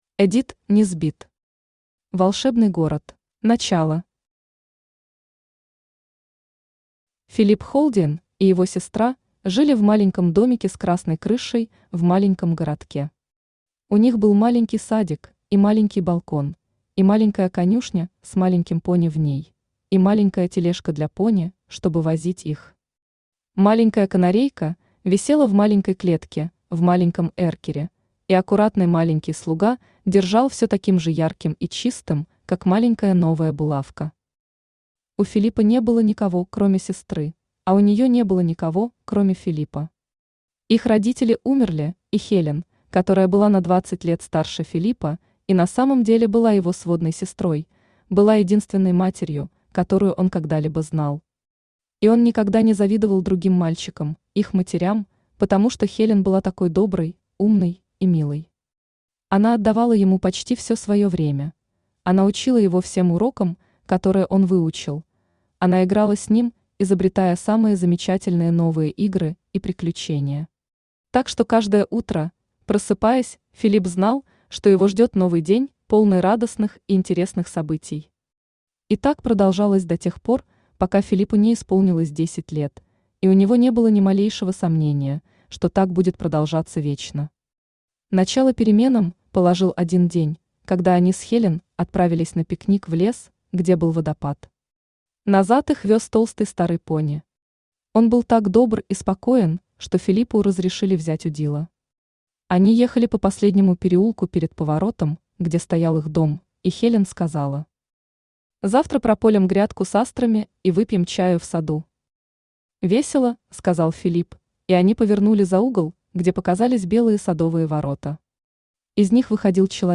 Аудиокнига Волшебный город | Библиотека аудиокниг
Aудиокнига Волшебный город Автор Эдит Несбит Читает аудиокнигу Авточтец ЛитРес.